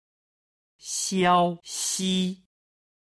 今日の振り返り中国語の音源
514-01-xiaoxi.mp3